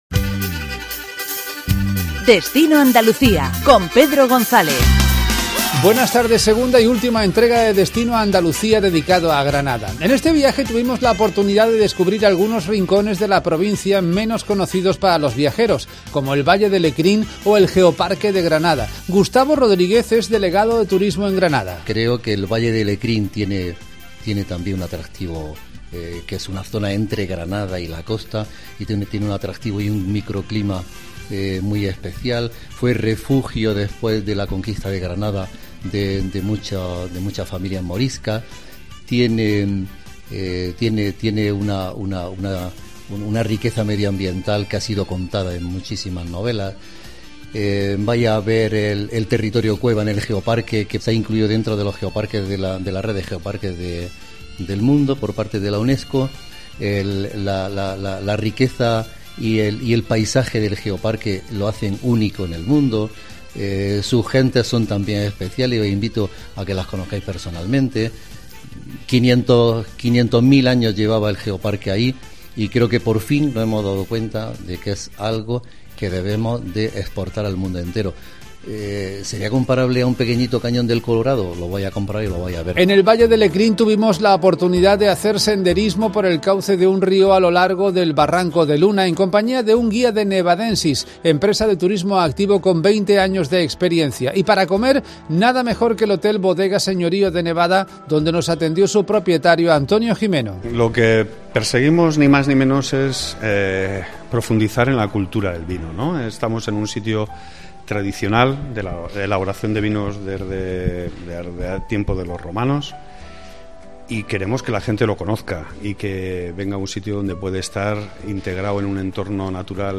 Tuvimos la oportunidad de descubrir algunos rincones de la provincia menos conocidos para lo viajeros, como el Valle de Lecrín o el Geoparque de Granada . EL VALLE DE LECRÍN Hablamos con el delegado de turismo de Junta de Andalucía en Granada, Gustavo Rodríguez, que nos contó algunas de las excelencias de esta preciosa y desconocida zona de la provincia de Granada El Valle de Lecrín es una comarca situada en la parte centro-sur de la provincia de Granada .